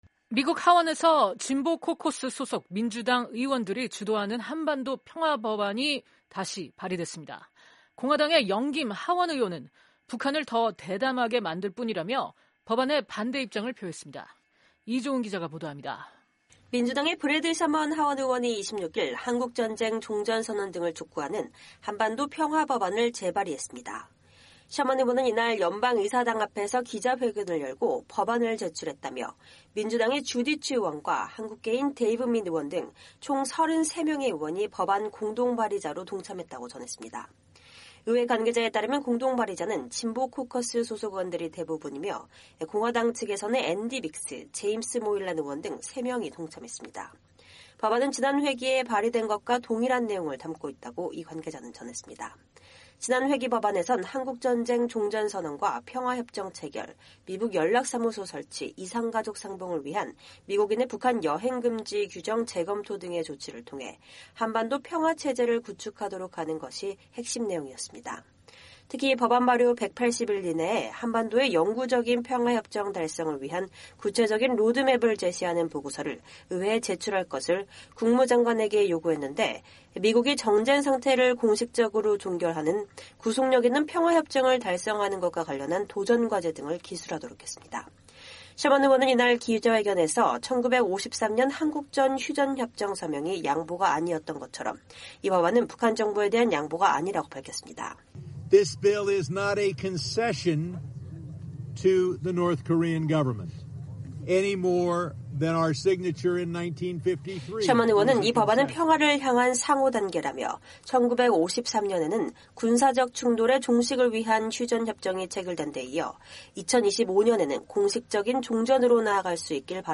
[녹취:셔먼 의원] “This bill is not a concession to the North Korean government, any more than our signature in 1953 was a concession. It is a mutual step toward peace. First, in 1953, to the cessation of military conflict. And now, hopefully in 2025, to the formal state of war. This bill is a designed to be put us in a confidence building measure because we have to start with the first step.”